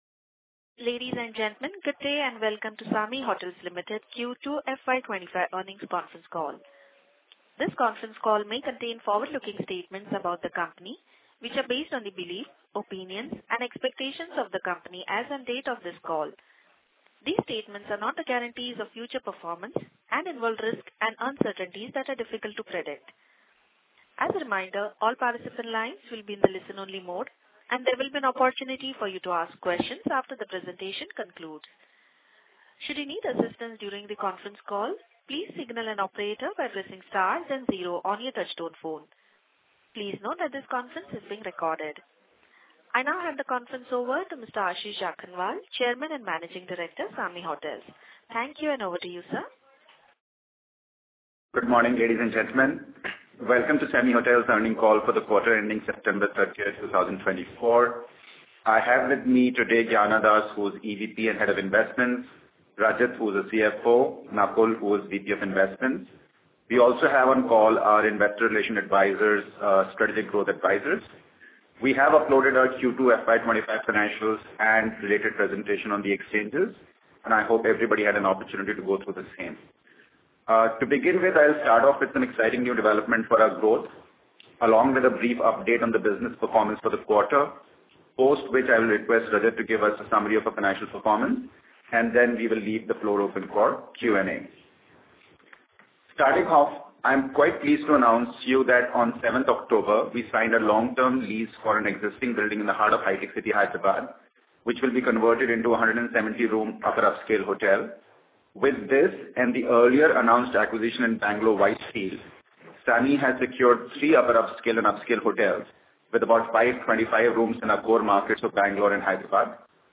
Concalls